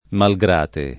[ mal g r # te ]